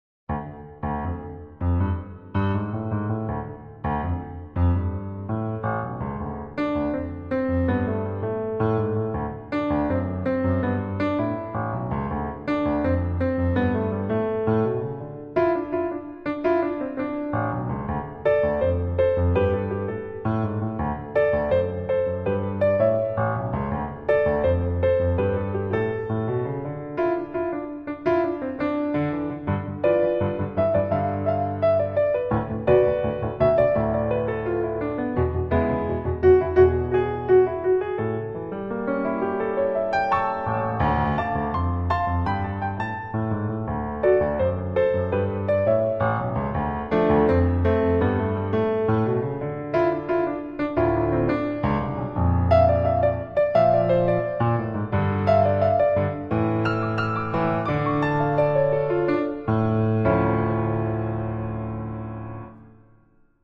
神秘有趣